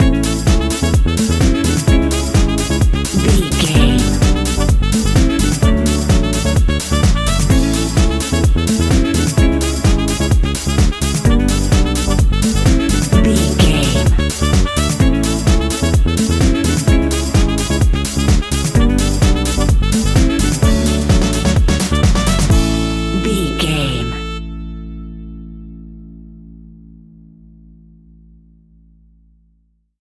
Ionian/Major
groovy
uplifting
bouncy
electric guitar
horns
drums
bass guitar
saxophone
upbeat
wah clavinet
synth bass